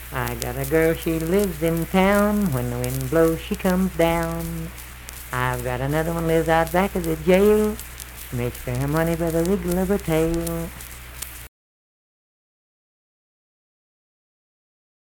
Unaccompanied vocal music performance
Verse-refrain 2(2).
Bawdy Songs
Voice (sung)